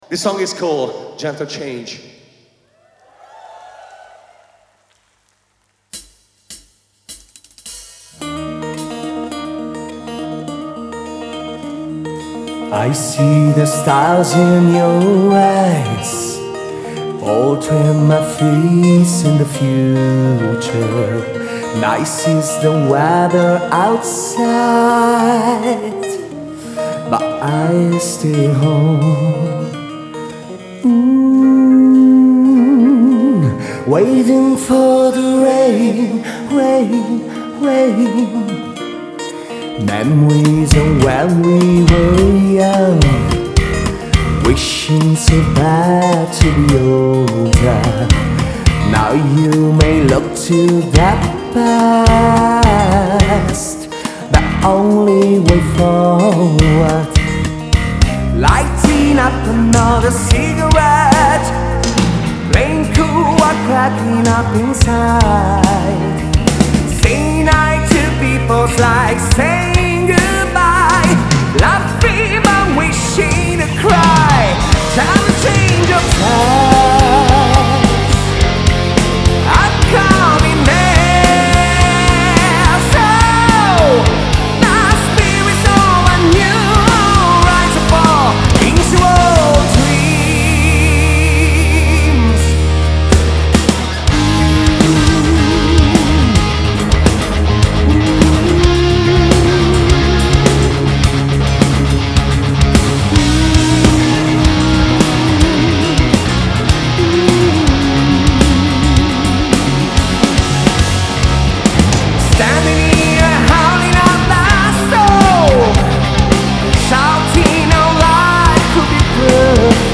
Genre: Metal
Recorded at Fascination Street Studios.